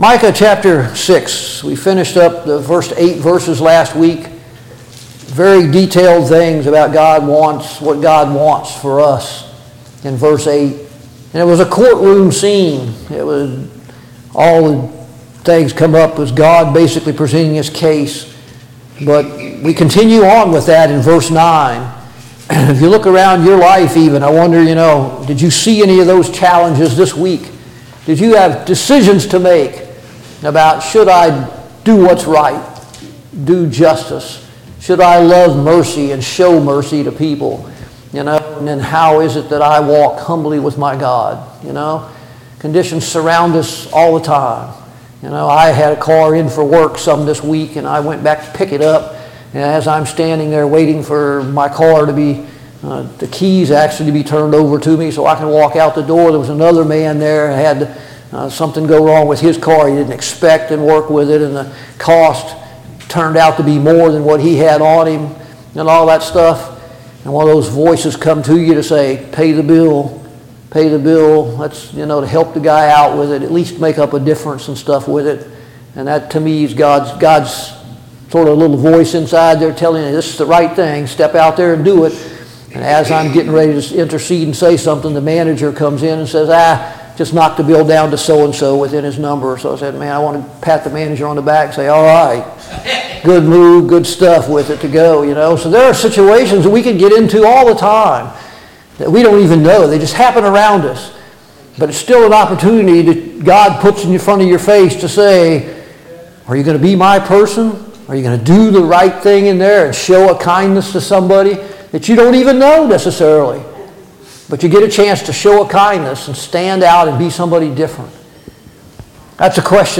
Study on the Minor Prophets Passage: Micah 6 Service Type: Sunday Morning Bible Class « 15.